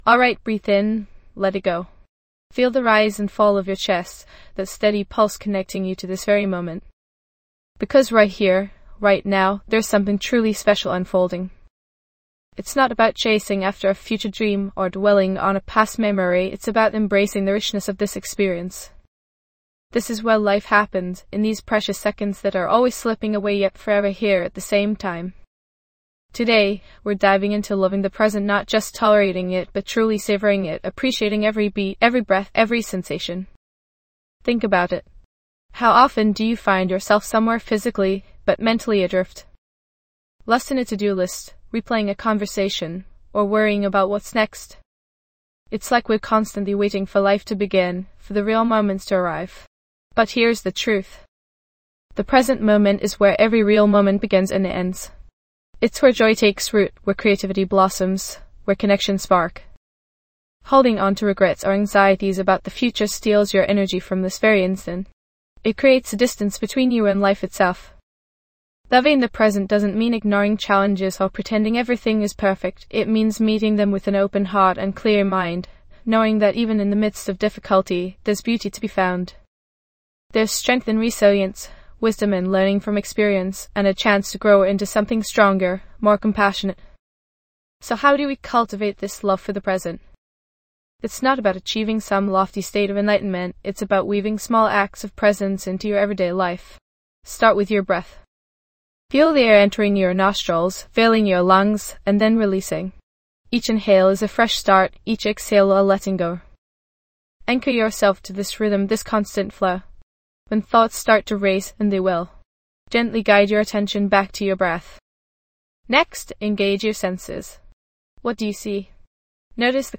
Let go of regrets from the past and anxieties about the future, and instead find peace in the here and now. This 10-minute guided meditation is designed to help…